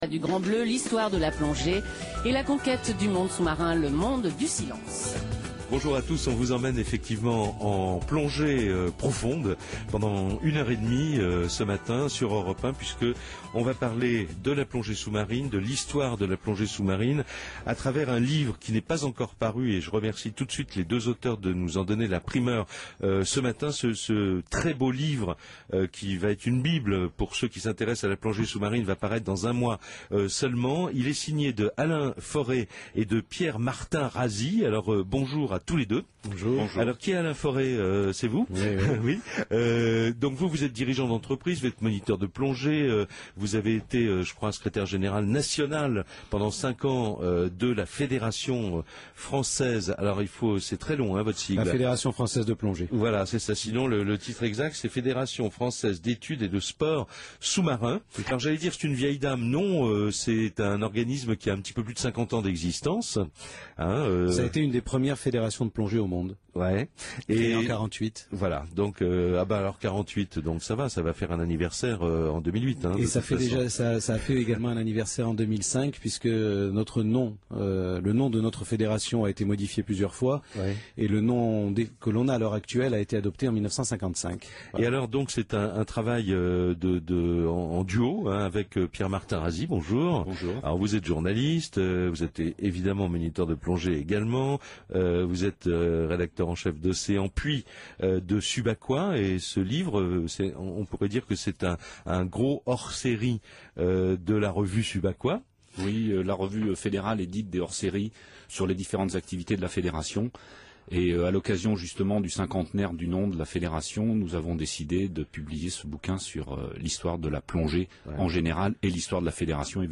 L’émission de présentation du livre sur Europe 1 avec Jacques Pradel – Ecouter…